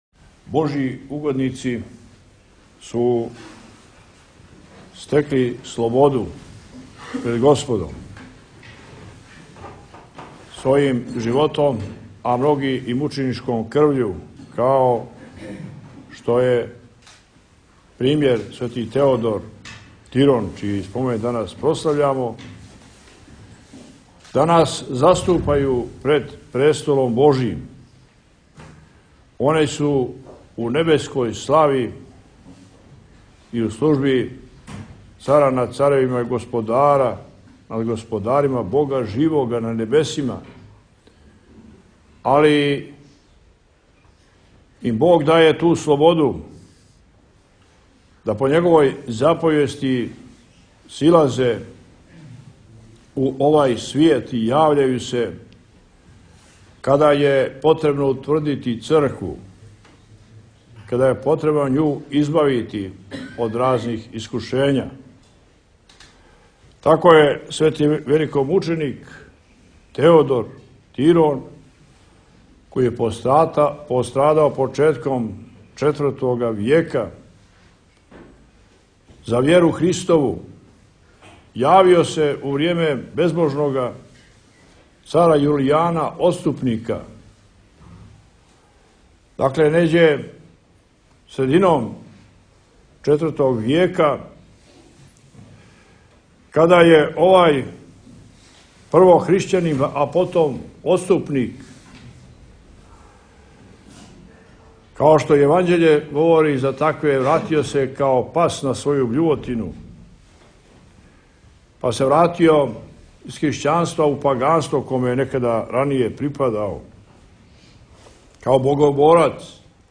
MITROPOLIT CETINJE LITURGIJA; Opis: Mitropolit Joanikije na Teodorovu subotu bogoslužio u Cetinjskom manastiru Tip: audio/mpeg
04.-03.-MITROPOLIT-CETINJE-LITURGIJA.m4a